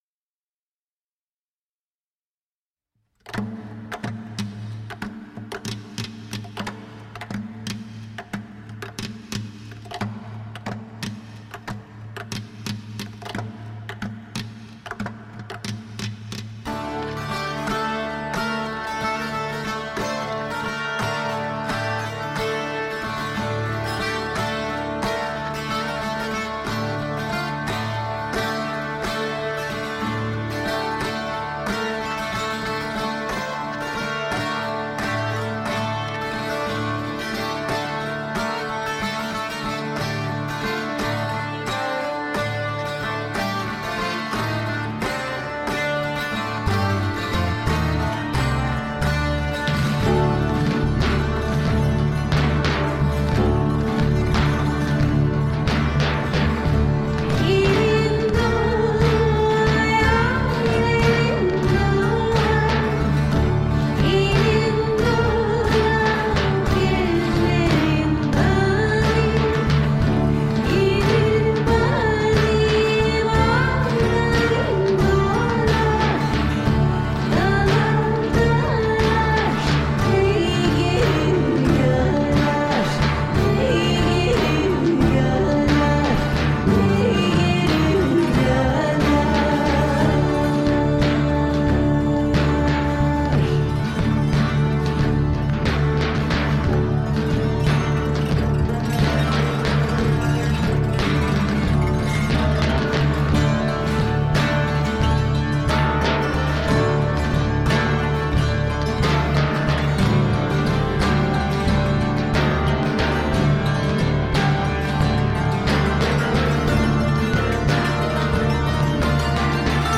Turkish rhythm called curcuna. Maqaam (scale) hicaz